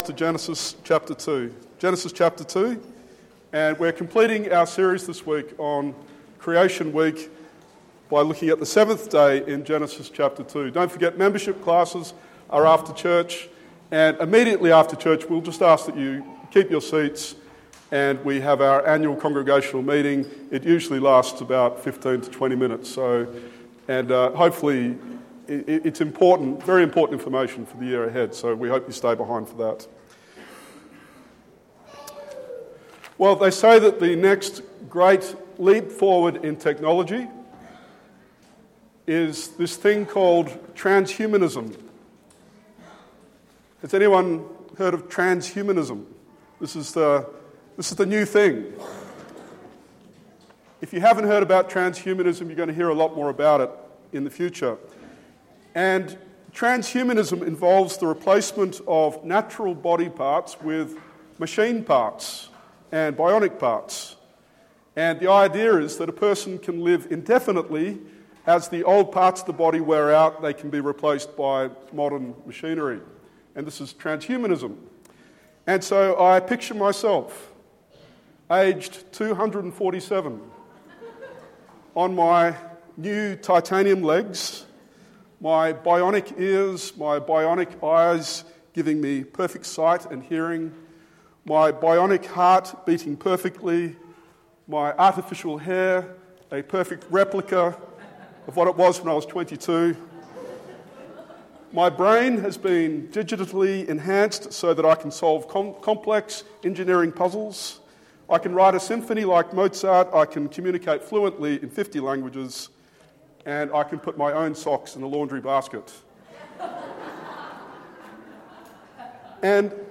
Genesis 1:1-2:3 Sermon